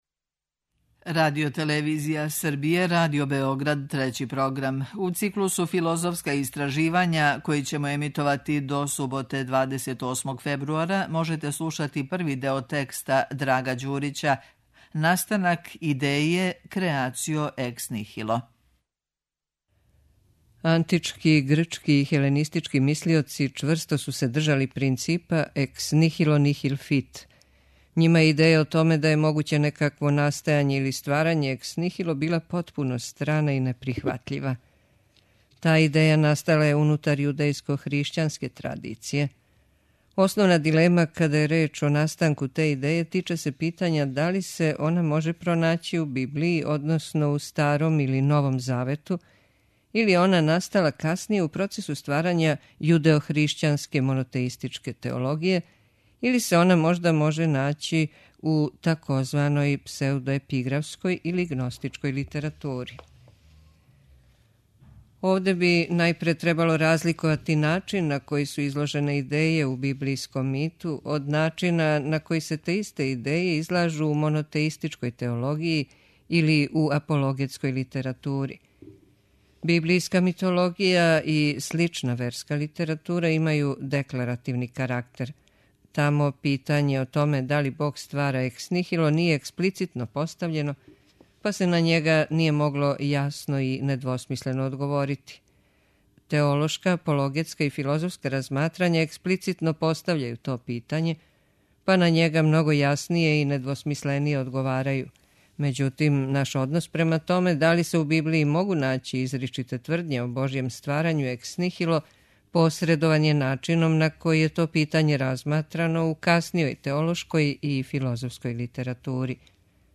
читаћемо текст